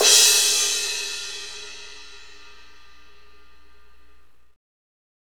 Index of /90_sSampleCDs/Northstar - Drumscapes Roland/KIT_Hip-Hop Kits/KIT_Rap Kit 3 x
CYM H H C0KL.wav